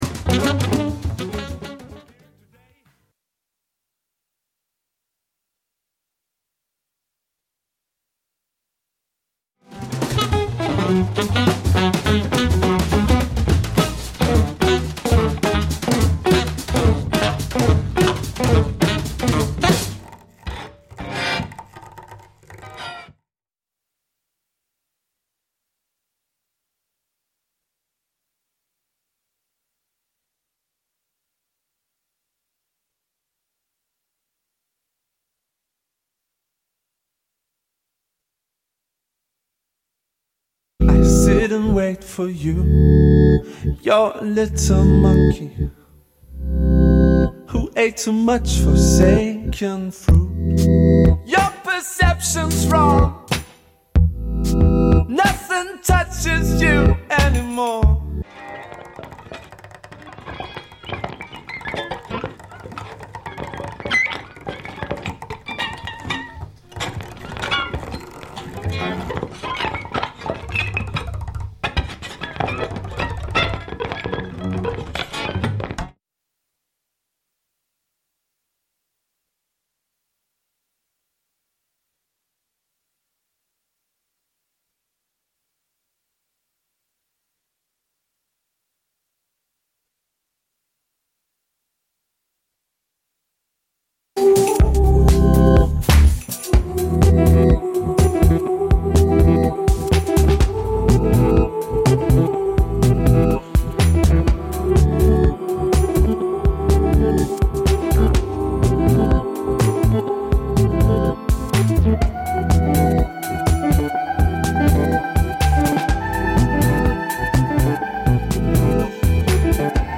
Wöchentlich präsentieren wir ausgesuchte Filmmusik.